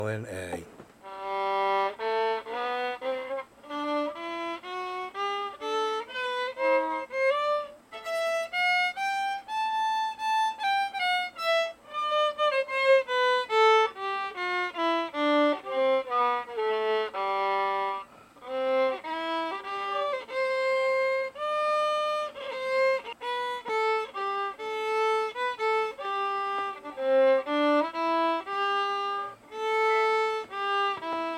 violin comparison.
I know the bowing is terrible and needs work.
I have notice since I started to record myself that there a something like a puck as I lift my fingers off the strings is this normal?
I thought initially that Violin B was a better sounding one and you played it better but after about 6 times of hearing the same stanza of notes I have changed my thinking to the violin A because it has better resonance or at least the bow and your playing are more compatible currently.
violin-a.mp3